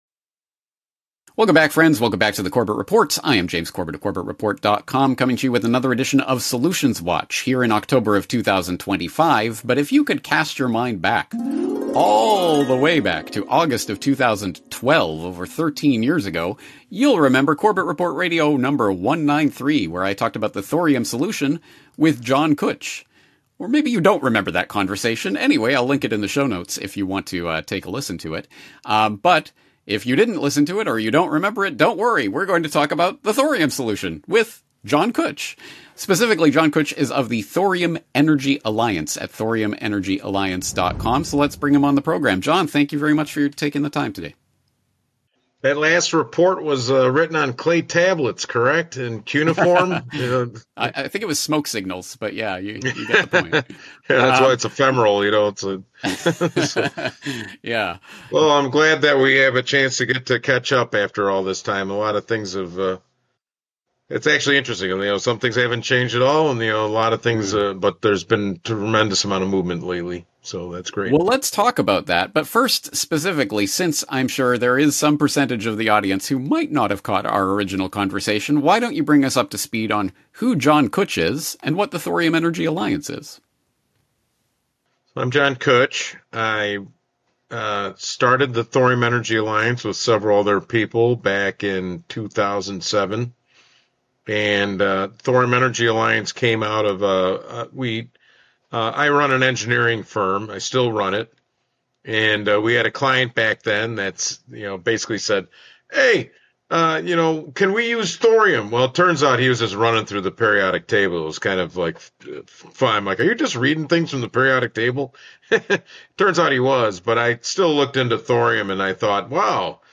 He also discusses the recent development of the first liquid-fueled thorium reactor in China, which represents a significant breakthrough in the field. The interview concludes with a discussion on the inherent safety of thorium fuel and the potential benefits of thorium-based liquid fuel reactors.